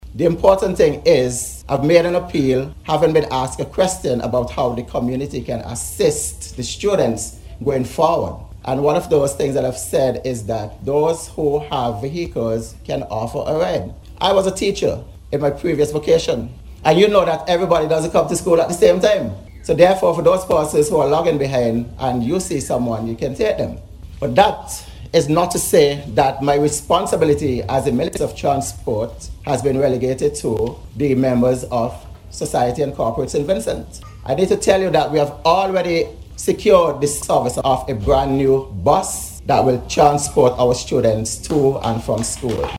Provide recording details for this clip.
He made this statement during a Thanksgiving ceremony which was held earlier this week, following last week’s fire.